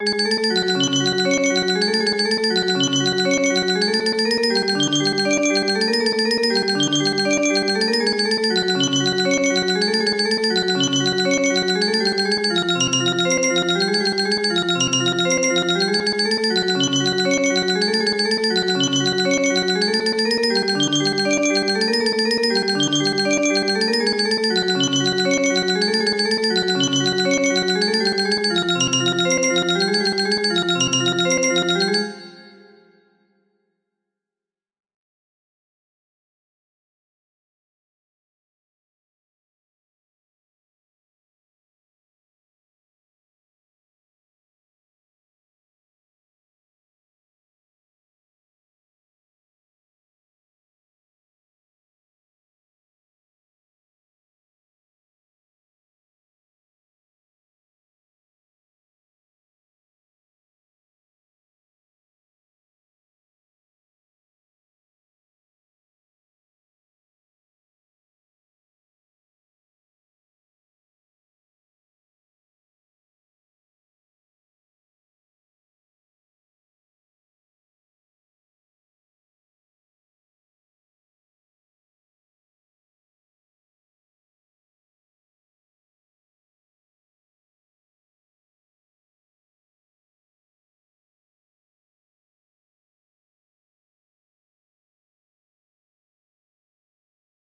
Track 4 music box melody
Full range 60